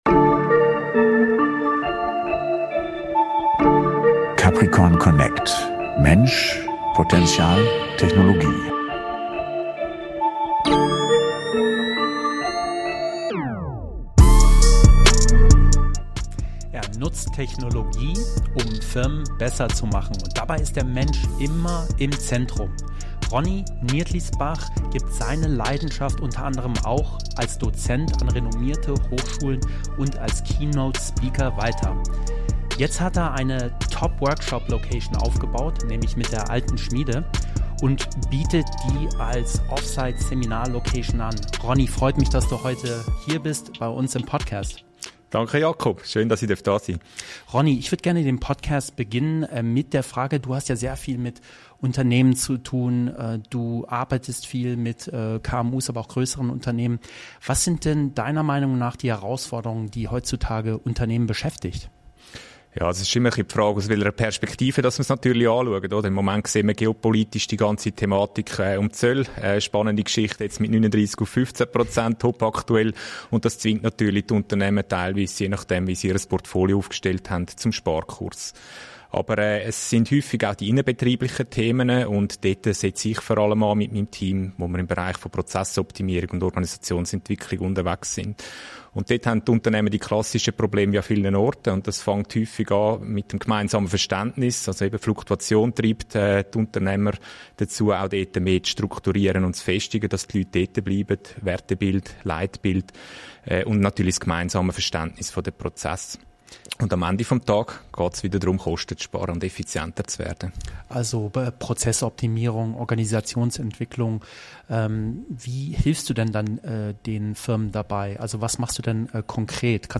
#90 - Interview